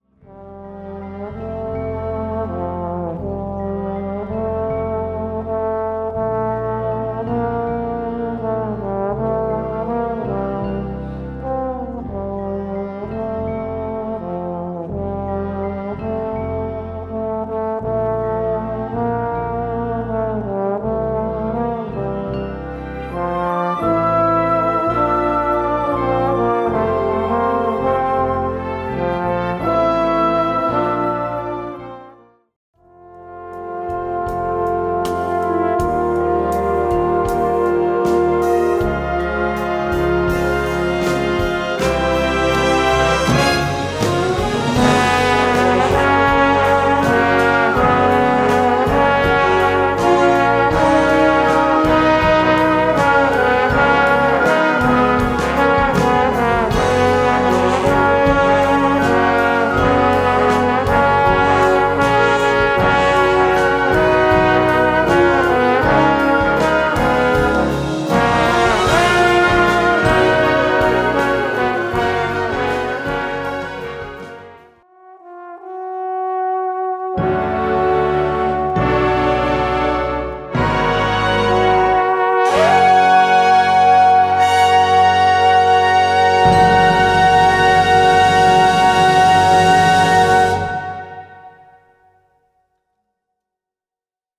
Gattung: Solo für Posaune
Besetzung: Blasorchester